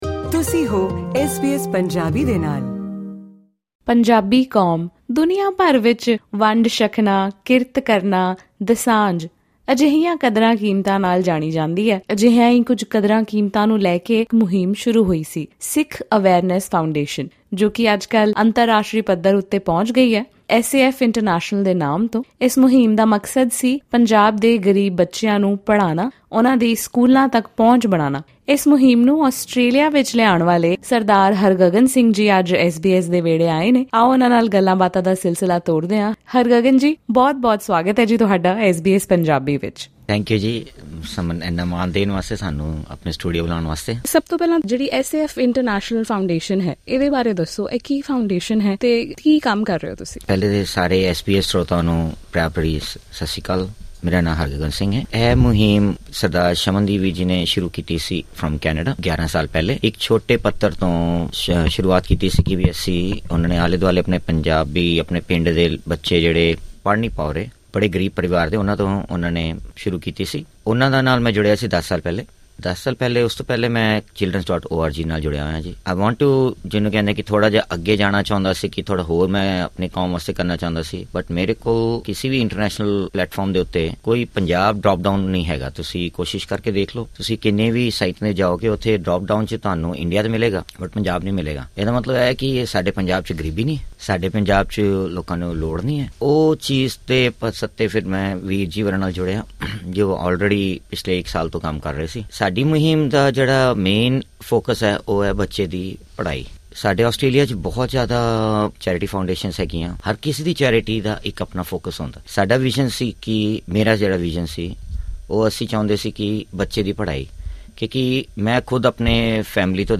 'ਸਿੱਖੀ ਅਵੇਅਰਨੈਸ ਫਾਊਂਡੇਸ਼ਨ' ਪੰਜਾਬ ਵਿੱਚ ਰਹਿੰਦੇ ਬੱਚਿਆਂ ਨੂੰ 'ਸਪਾਂਸਰਸ਼ਿਪ' ਦਿਲਵਾ ਕੇ ਉਨ੍ਹਾਂ ਨੂੰ ਪੜ੍ਹਾਈ ਕਰਵਾਉਂਦੀ ਹੈ। ਉਨ੍ਹਾਂ ਦਾ ਮੰਨਣਾ ਹੈ ਕਿ ਇੱਕ ਬੱਚੇ ਦੀ ਪੜ੍ਹਾਈ ਪੂਰੇ ਪਰਿਵਾਰ ਦੀ ਗਰੀਬੀ ਖ਼ਤਮ ਕਰ ਸਕਦੀ ਹੈ। ਹੋਰ ਵੇਰਵੇ ਲਈ ਇਹ ਖਾਸ ਇੰਟਰਵਿਊ ਸੁਣੋ...